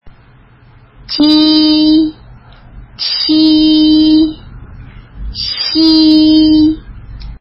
舌面音
j(i) (無気音)舌面の前を上あごにつけて、弱い息で｢ジー｣と発音するイメージ。
q(i) (有気音)舌面の前を上あごにつけて、強い息で｢チー｣と発音するイメージ。
x(i) 舌の根元を上あごに近づけ、息で舌を擦るように｢シー｣と発音するイメージ。